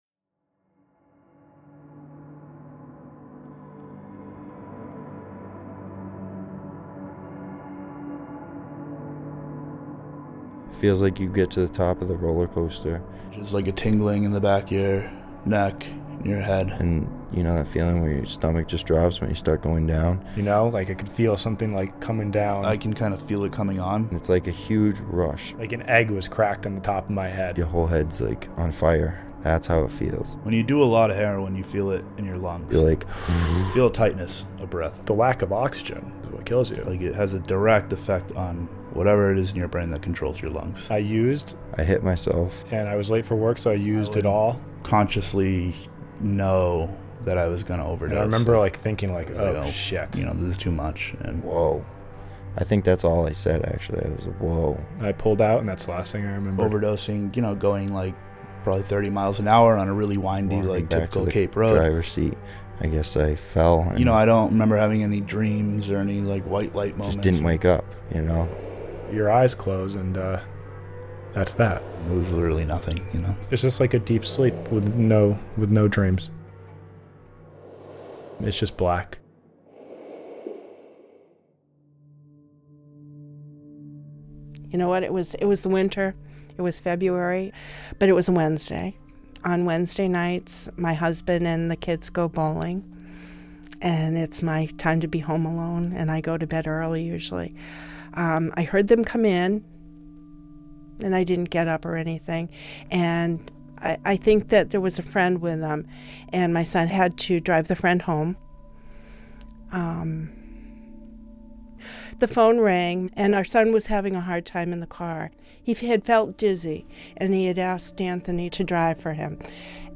It was important [for me] not to editorialize the experiences of the people I was talking to. I wanted them to tell their own stories in their own words. I wanted the listener to be immersed in their lives for a few minutes, and to hear the truth in their voices.”